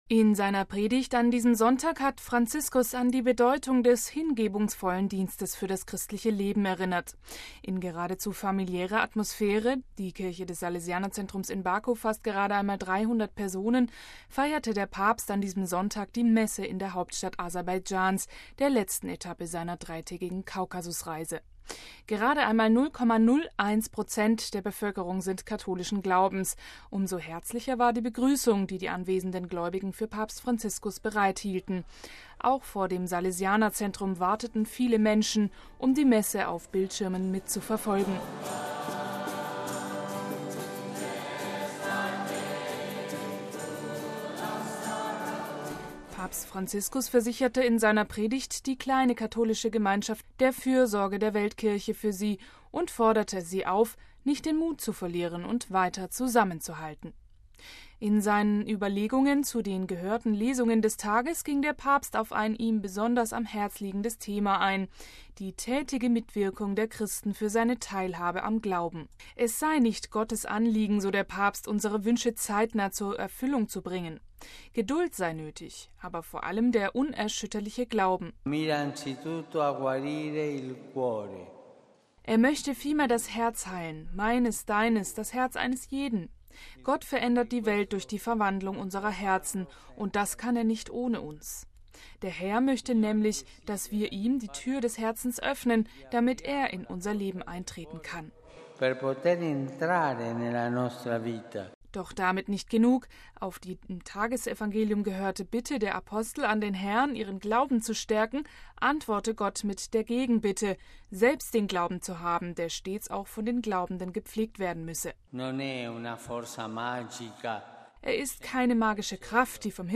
In seiner Predigt an diesem Sonntag hat Franziskus an die Bedeutung des hingebungsvollen Dienstes für das christliche Leben erinnert. In geradezu familiärer Atmosphäre – die Kirche des Salesianer-Zentrums in Baku fasst gerade einmal 300 Personen – feierte der Papst an diesem Sonntag die Messe in der Hauptstadt Aserbaidschans, der letzten Etappe seiner dreitägigen Kaukasusreise.